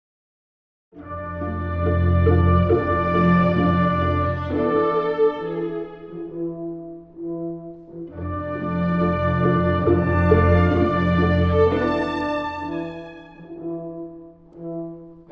↑古い録音のため聴きづらいかもしれません！（以下同様）
メンデルスゾーンの「無言歌」という歌曲風の楽章です。
愛とも、悲劇とも取れるような主題。
一種のドラマ性が感じられます。